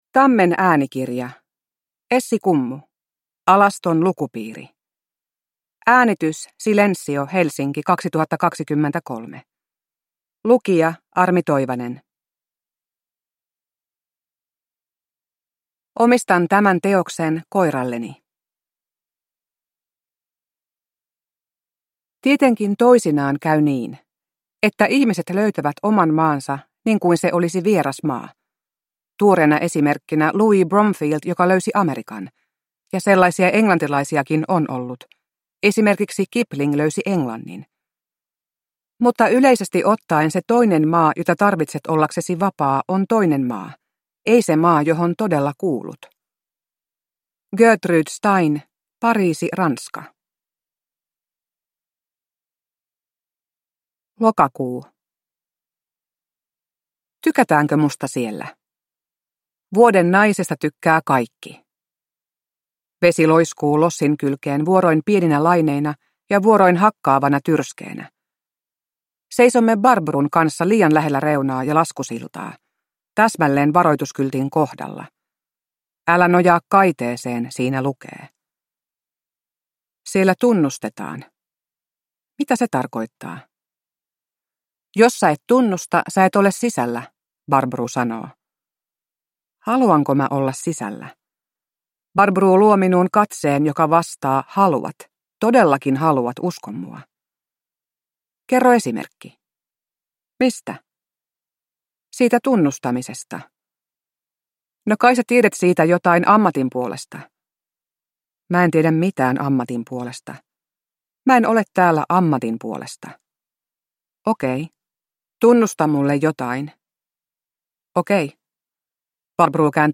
Alaston lukupiiri – Ljudbok